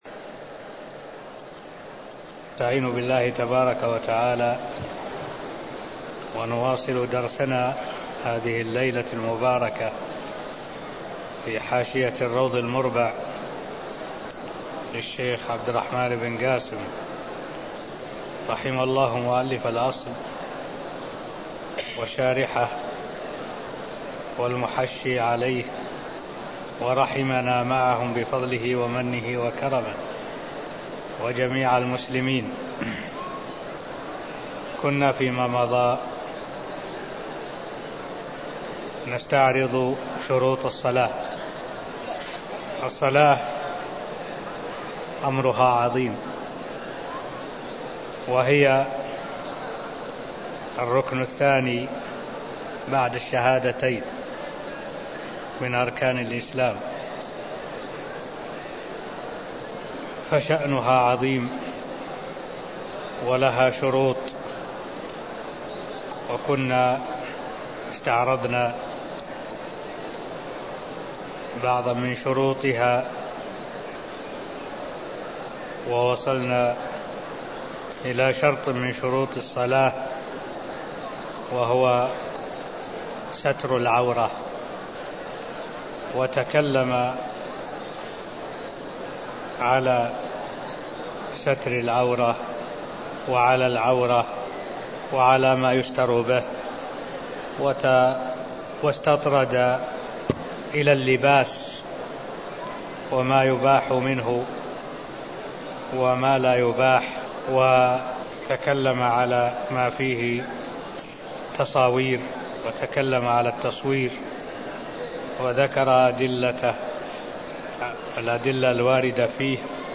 المكان: المسجد النبوي الشيخ: معالي الشيخ الدكتور صالح بن عبد الله العبود معالي الشيخ الدكتور صالح بن عبد الله العبود شروط الصلاة-ستر العورة (0018)